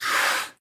Minecraft Version Minecraft Version 25w18a Latest Release | Latest Snapshot 25w18a / assets / minecraft / sounds / mob / dolphin / blowhole1.ogg Compare With Compare With Latest Release | Latest Snapshot
blowhole1.ogg